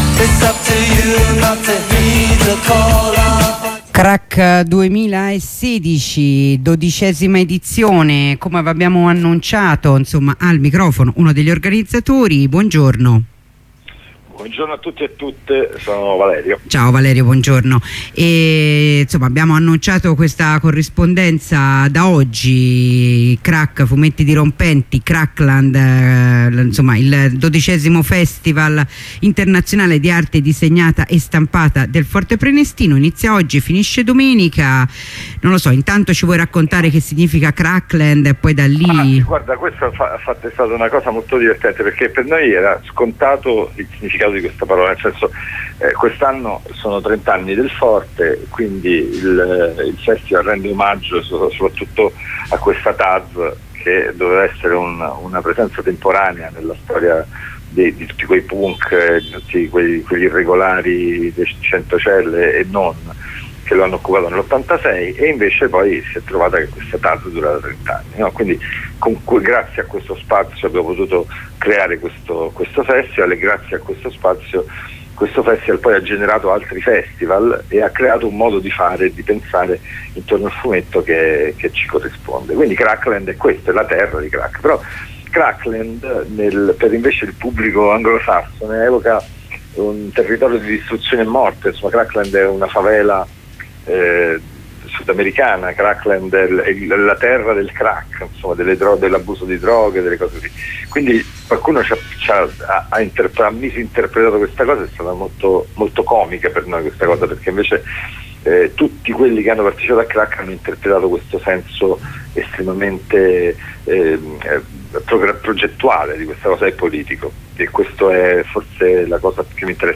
Corrispondenza con compagna di Lucha y siesta